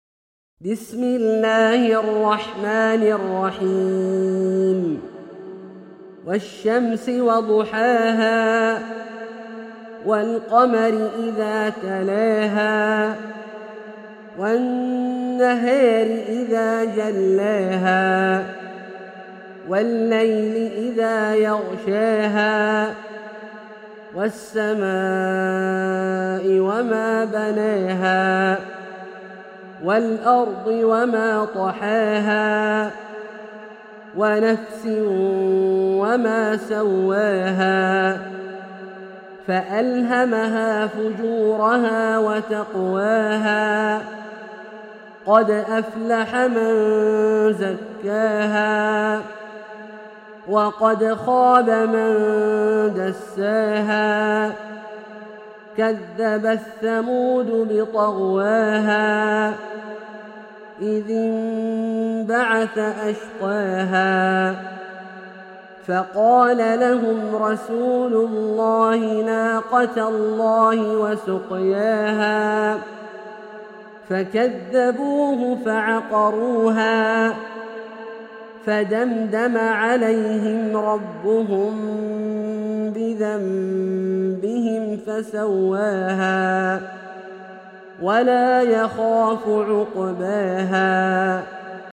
سورة الشمس - برواية الدوري عن أبي عمرو البصري > مصحف برواية الدوري عن أبي عمرو البصري > المصحف - تلاوات عبدالله الجهني